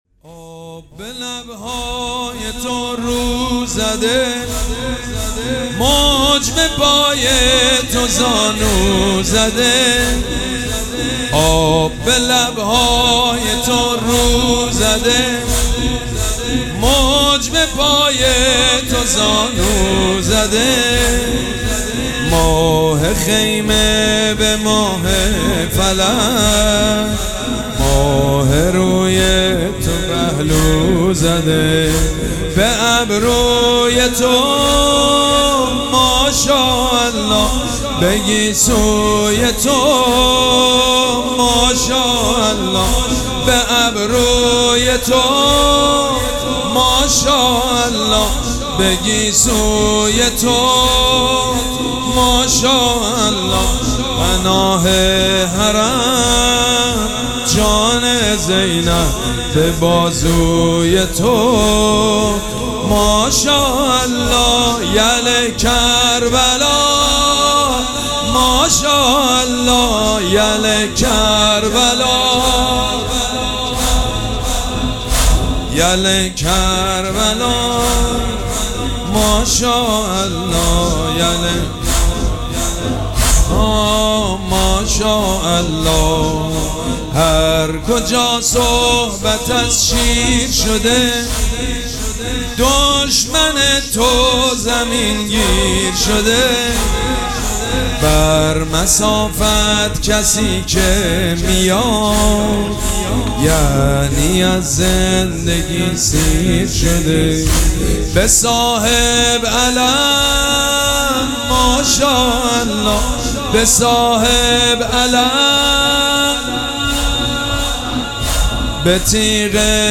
شب چهارم مراسم عزاداری اربعین حسینی ۱۴۴۷
حاج سید مجید بنی فاطمه